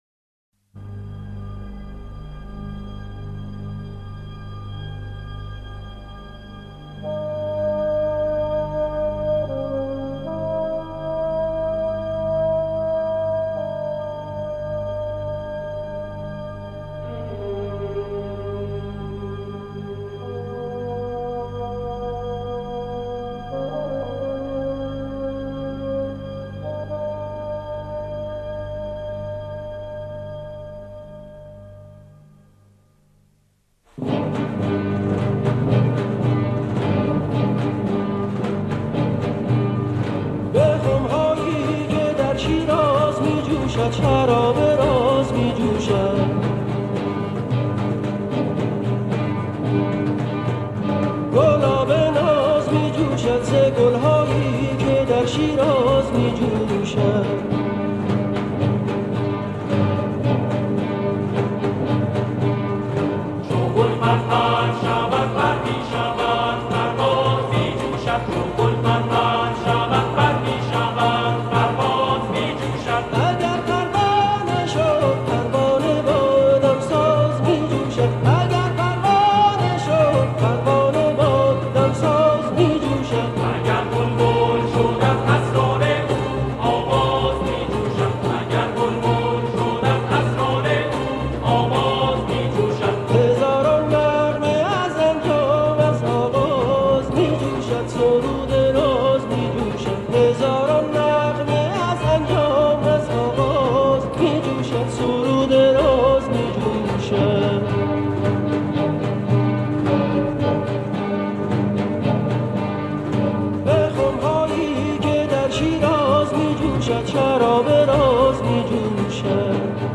قطعه